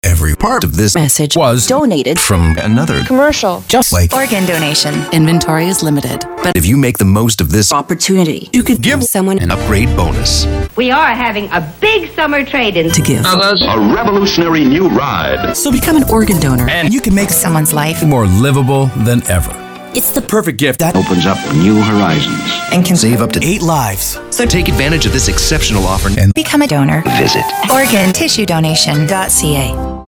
La campagne canadienne « The Donated Commercial » a remporté l’Or. Pour l’organisme de promotion du don d’organes, cette campagne a « récupéré »  des extraits de messages radio avec des mots et voix différents afin d’illustrer le fait que l’on peut reconstruire à partir d’éléments donnés.